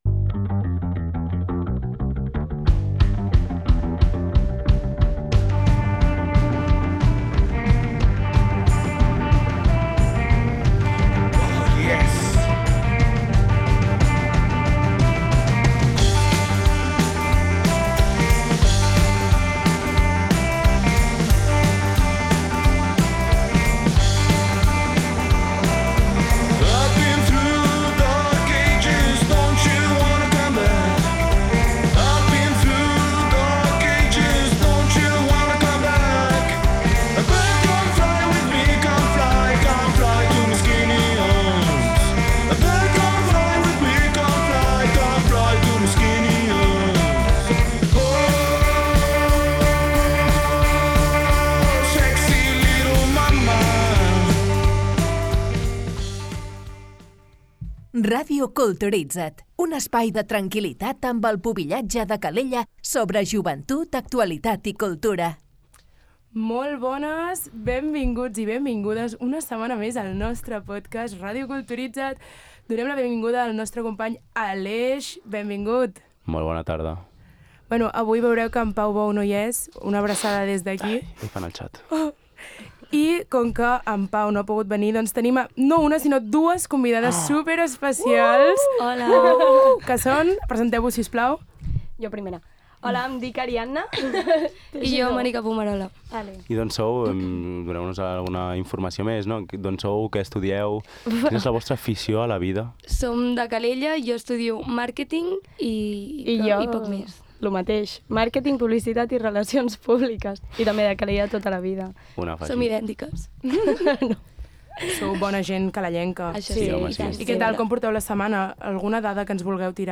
Amb elles introduirem diferents aspectes sobre el pubillatge, societat i cultura en un espai de tertúlia, debats i fets curiosos des de la perspectiva dels joves.